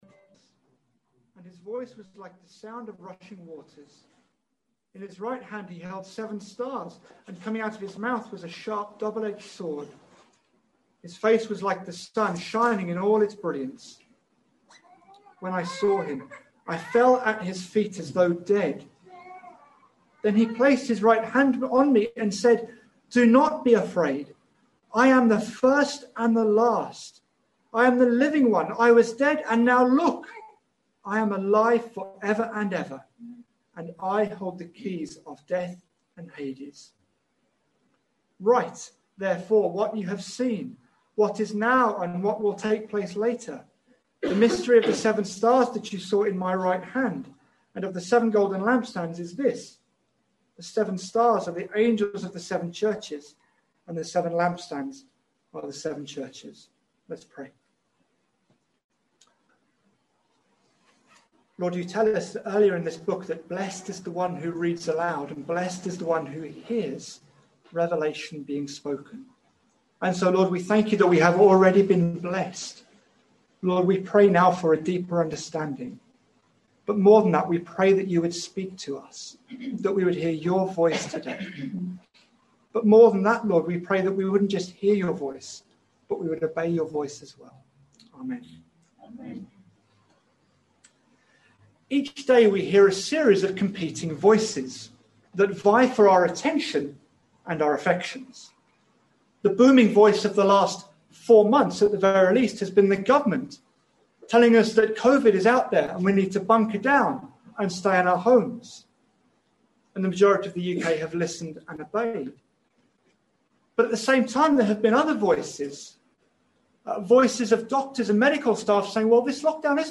Sunday 2nd may 2021 sermon.mp3